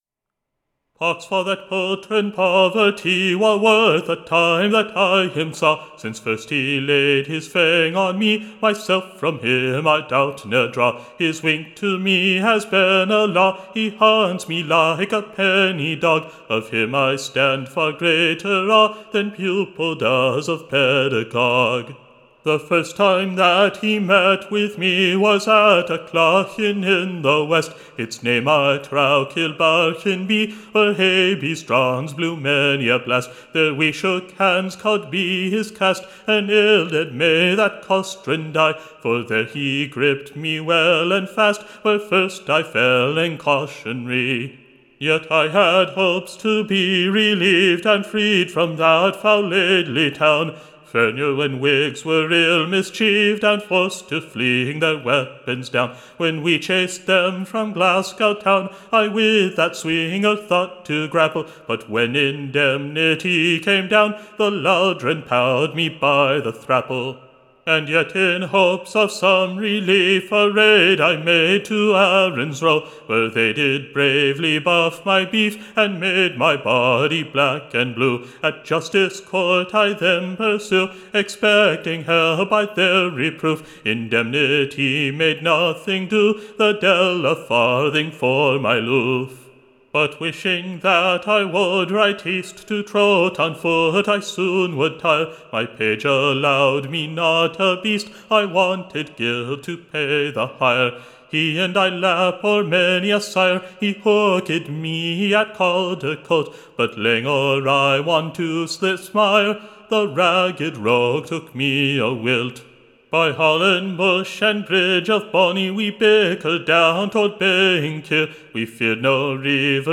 Recording Information Ballad Title The Banishment of Poverty by his Royal / Highness, Tune Imprint To the Tune of the Last Good-night. Standard Tune Title Essex' Last Good-night Media Listen 00 : 00 | 18 : 39 Download Ry1.2.mp3 (Right click, Save As)